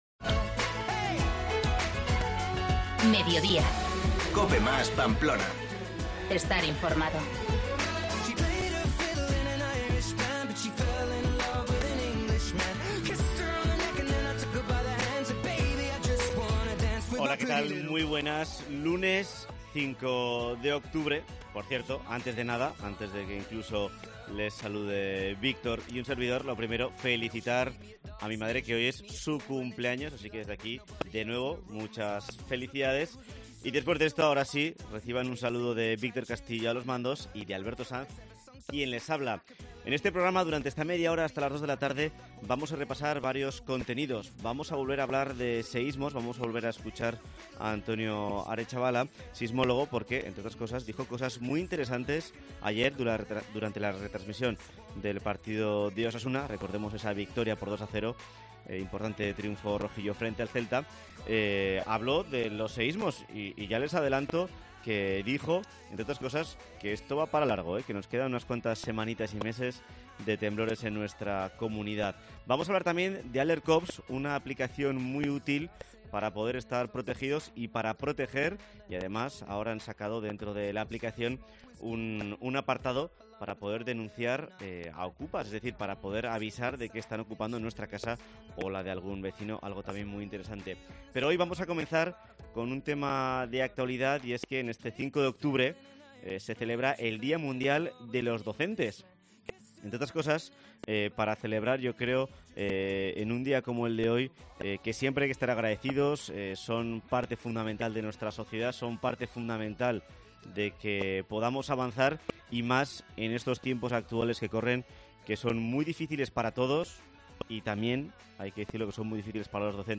Entrevista con Javier Esparza, docente y presidente de UPN, para repasar la actualidad de Educación en Navarra